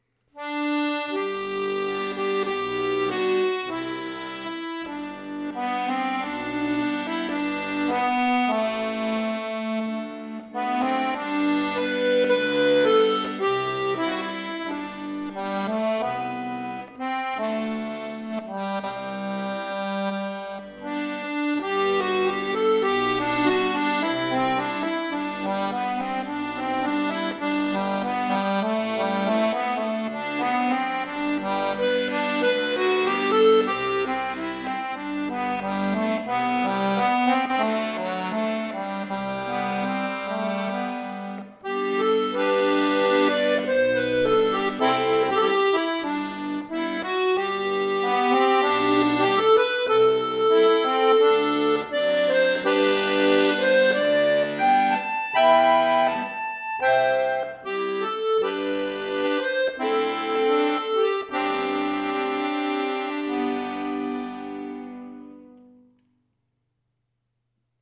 Irisch , Folk